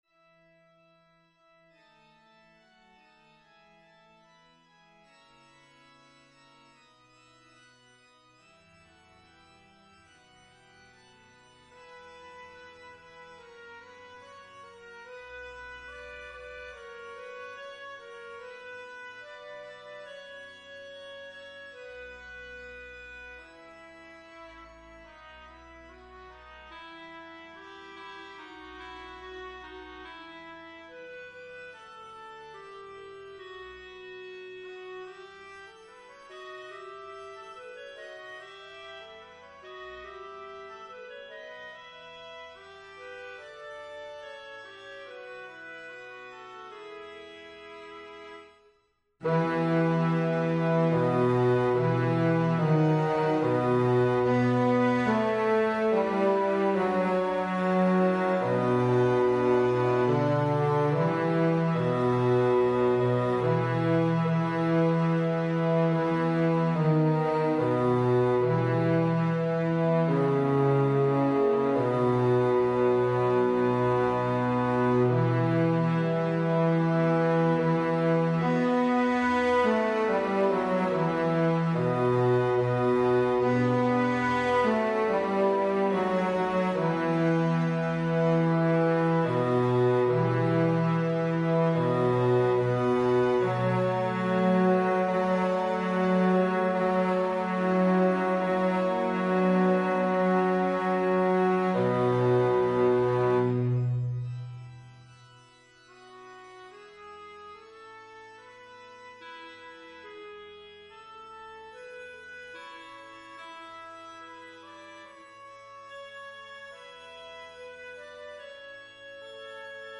Bass
Evensong Setting